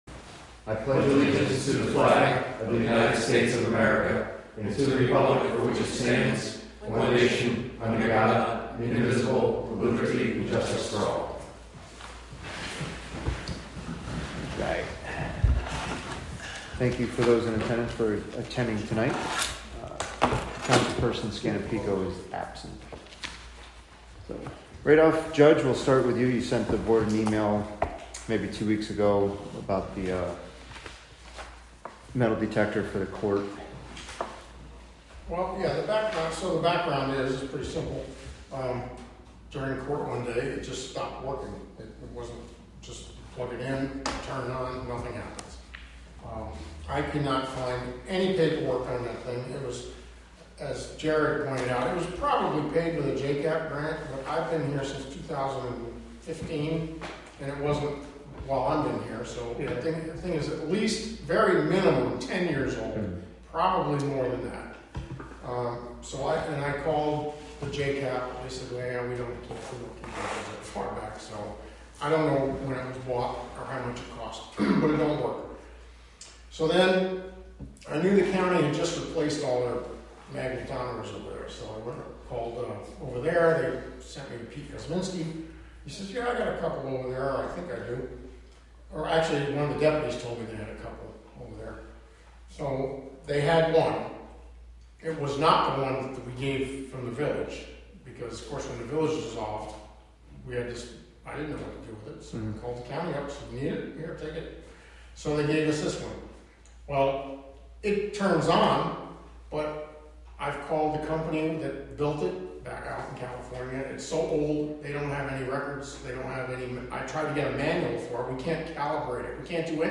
Live from the Town of Catskill: July 16 2025 Catskill Town Board Meeting (Audio)